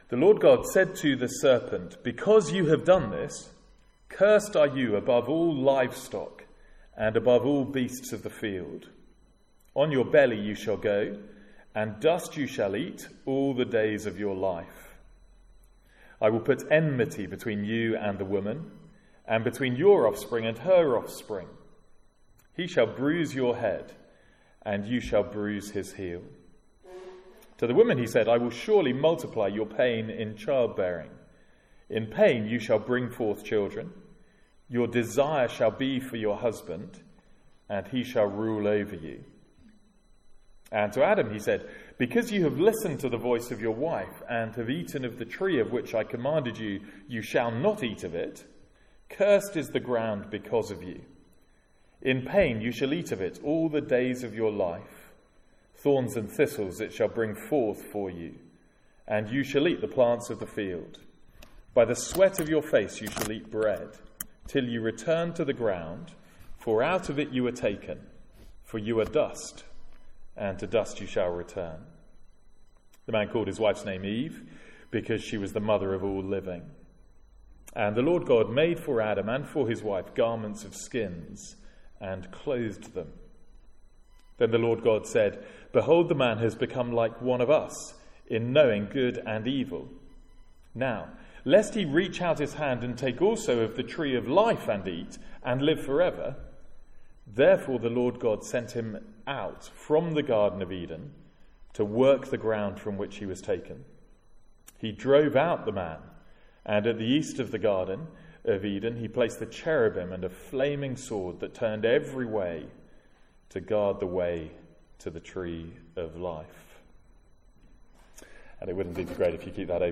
Sermons | St Andrews Free Church
From our evening series in Genesis.